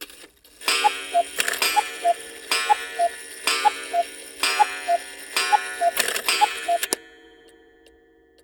cuckoo-clock-07.wav